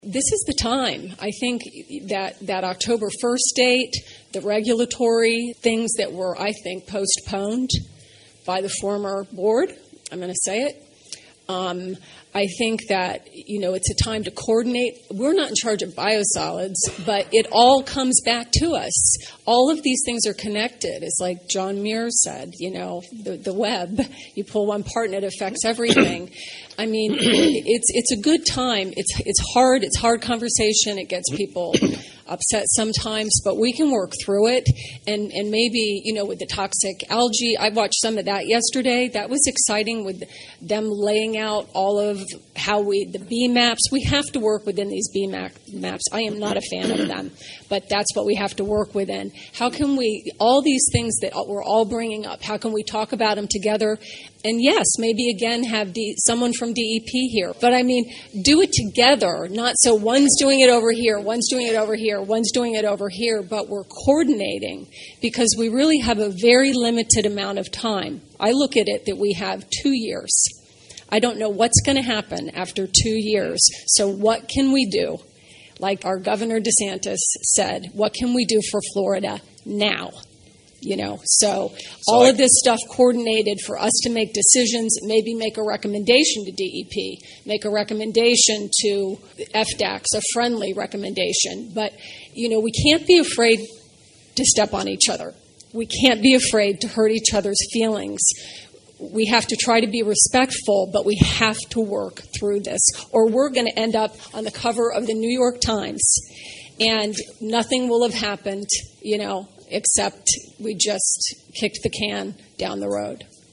Early in Thursday’s SFWMD meeting, governing board member Jacqui Thurlow-Lippisch shared her concerns about time being of the essence to make decisions as a board. She offered comments targeting the limited time frame available for this SFWMD board to make a difference: